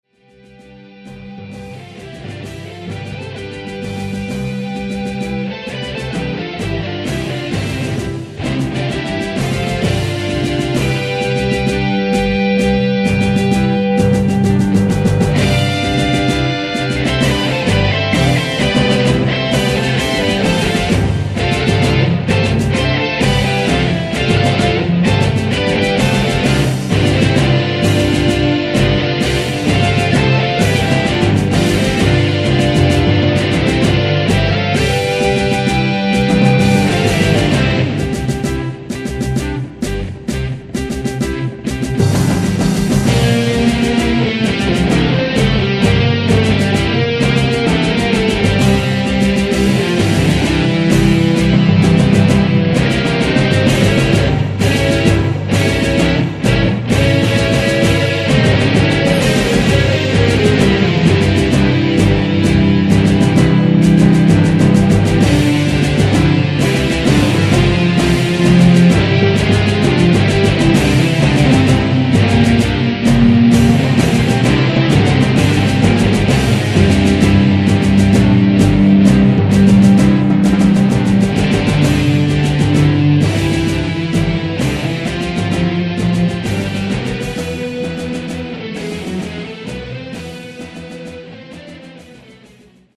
gitarre,programming
sopran- tenor- baritonsaxophone